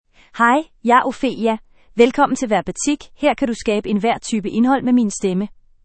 Ophelia — Female Danish AI voice
Ophelia is a female AI voice for Danish (Denmark).
Voice sample
Listen to Ophelia's female Danish voice.
Female